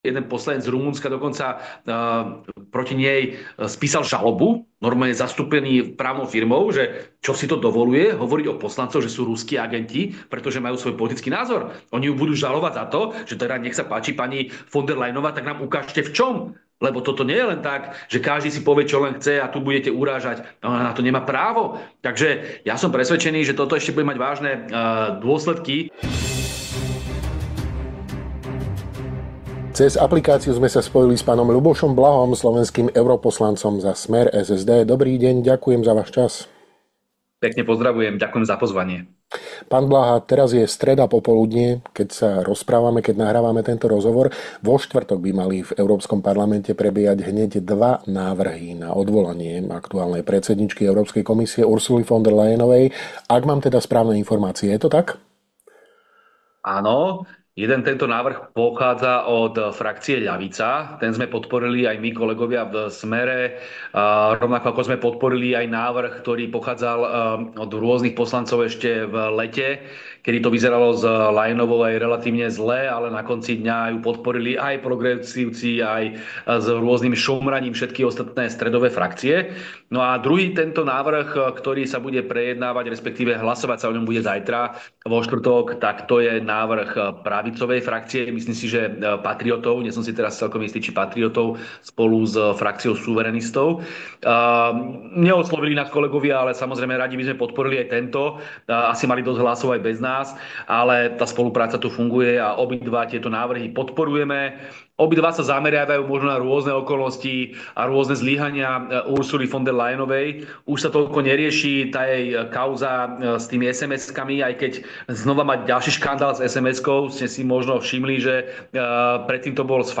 Dozviete sa vo videorozhovore s europoslancom za SMER – SSD, PhDr. Ľubošom Blahom, PhD.